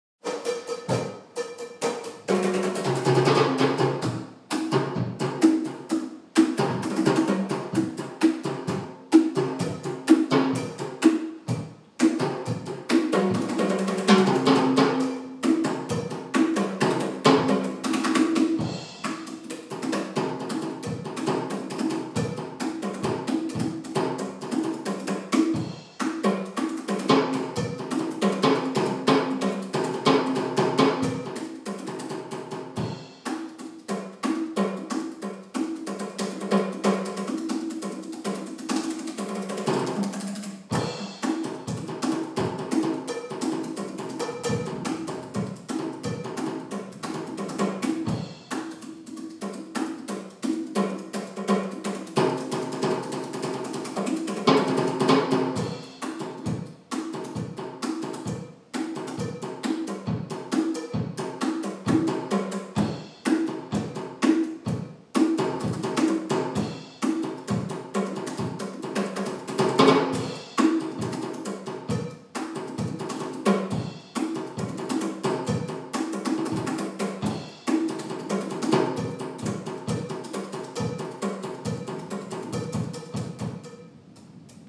Drum Warmup
drum-warmup.m4a